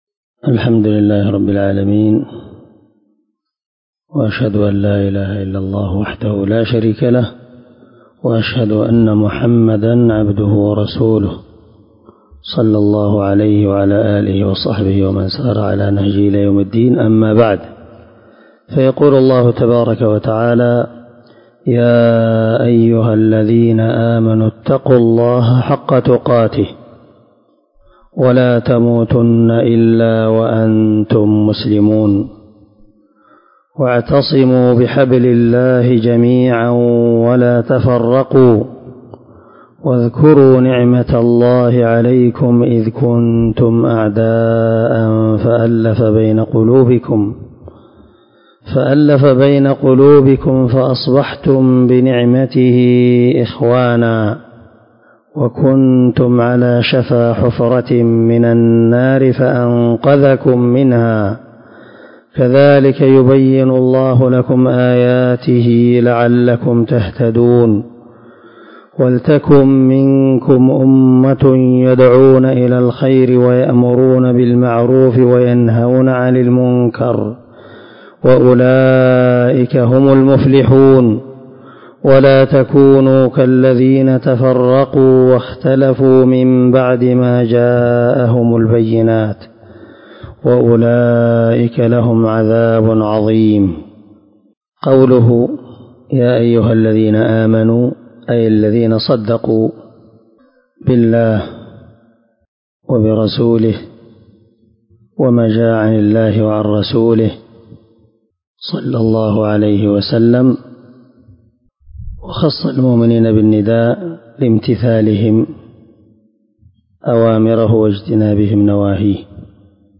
187الدرس 32 تفسير آية( 102 – 105 )من سورة آل عمران من تفسير القران الكريم مع قراءة لتفسير السعدي